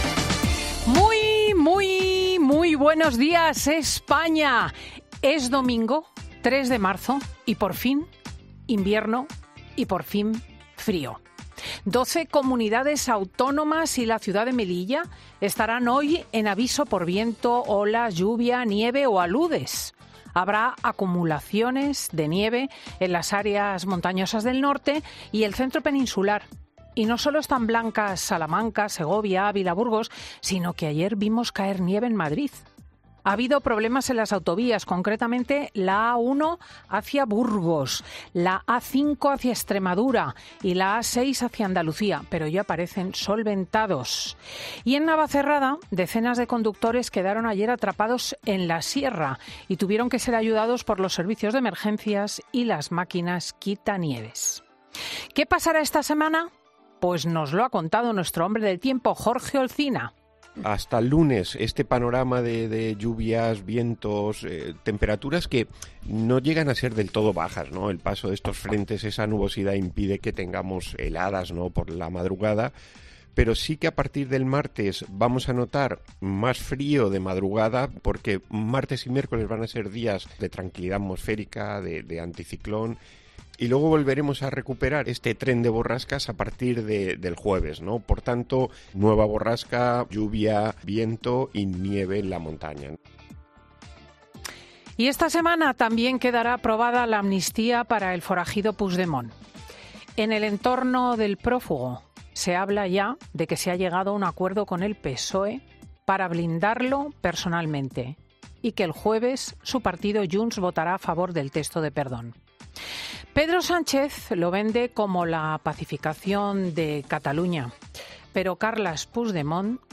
Monólogo de Cristina López Schlichting
Habla la presentadora de 'Fin de Semana' de la amnistía y de Puigdemont en plena trama del caso Koldo